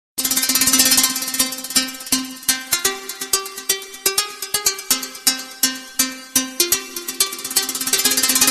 Musik, Arabisk Musik, Android